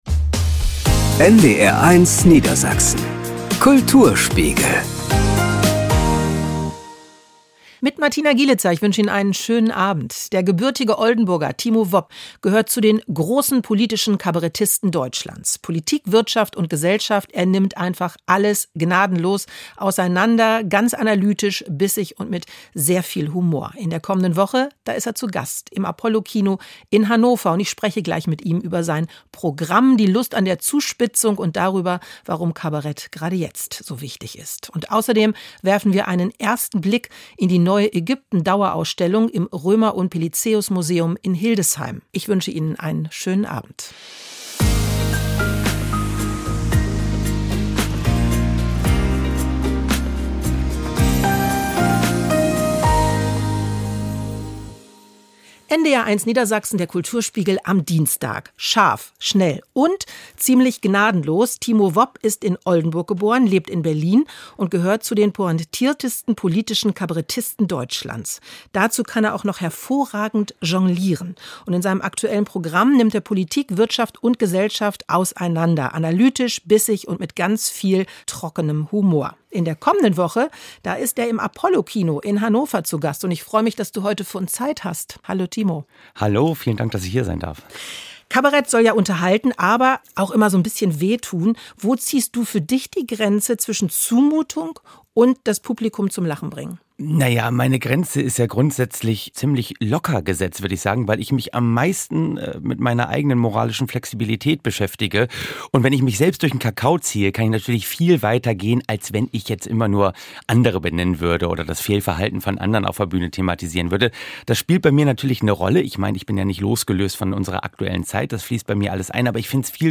Nun ist er zu Gast bei Moderatorin